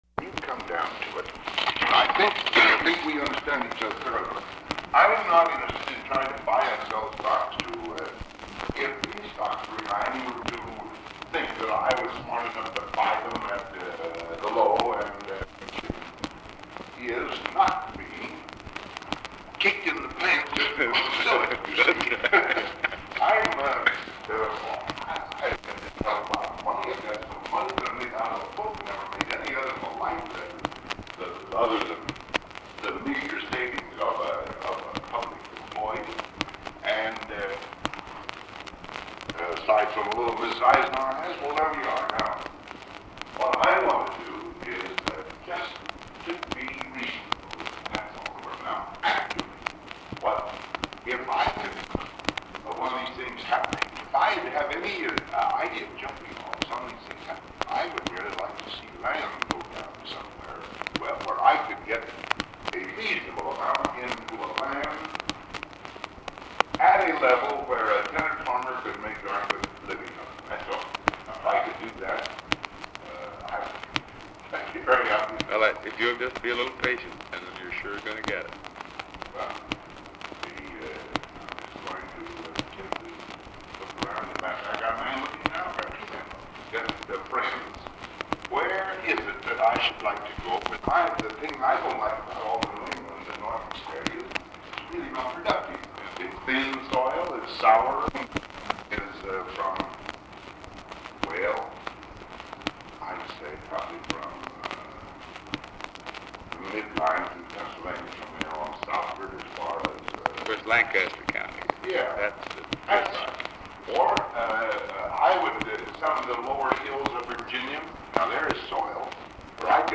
The recording begins with the conversation already in progress. Eisenhower and his guest are talking about investments to increase individual income.
Secret White House Tapes